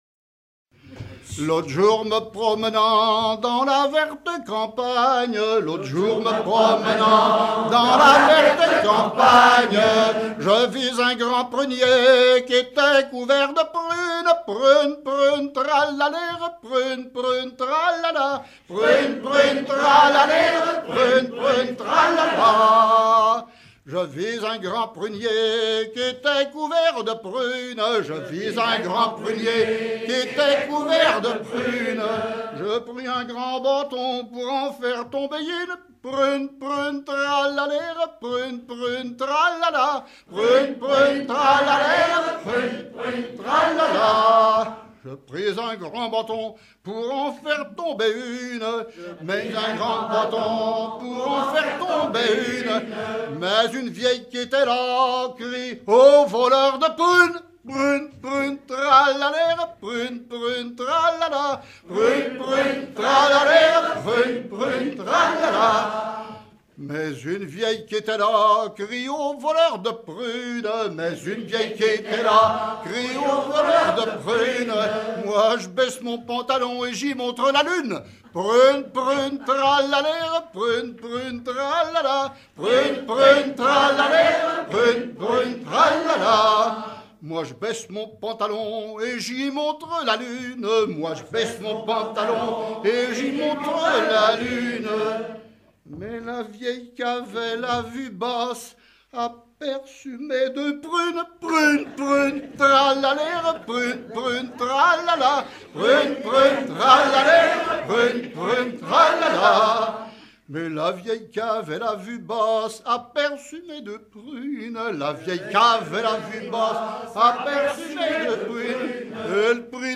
Saint-Aubin-des-Ormeaux
Genre laisse